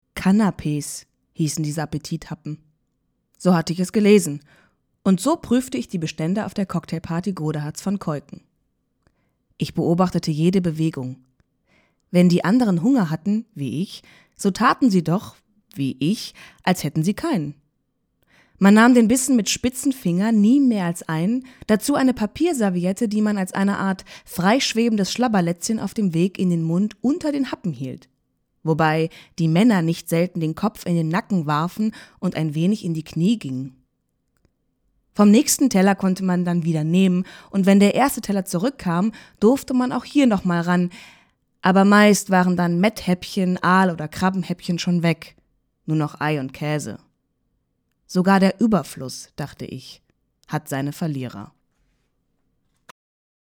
rheinisch
Sprechprobe: Sonstiges (Muttersprache):